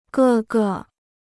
各个 (gè gè) Dictionnaire chinois gratuit